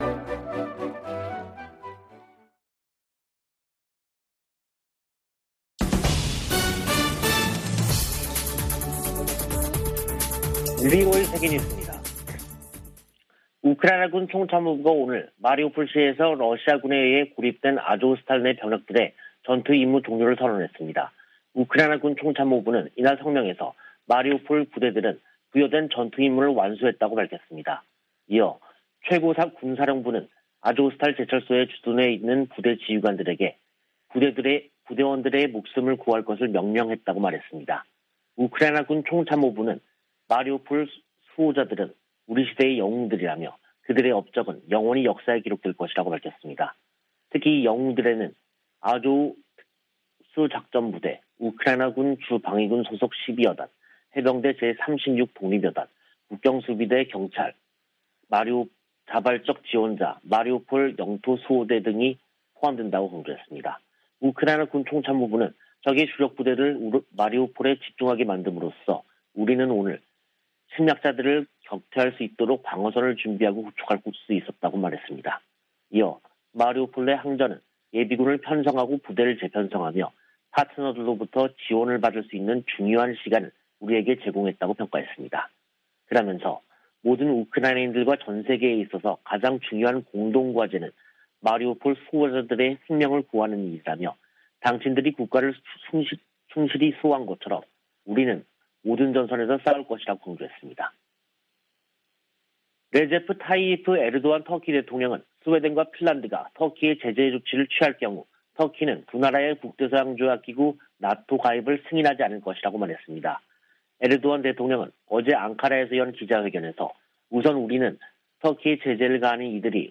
VOA 한국어 간판 뉴스 프로그램 '뉴스 투데이', 2022년 5월 17일 2부 방송입니다. 북한은 연일 신종 코로나바이러스 감염증 발열자가 폭증하는 가운데 한국 정부의 방역 지원 제안에 답하지 않고 있습니다. 세계보건기구가 북한 내 급속한 코로나 확산 위험을 경고했습니다. 북한의 IT 기술자들이 신분을 숨긴 채 활동하며 거액의 외화를 벌어들이고 있다고 미국 정부가 지적했습니다.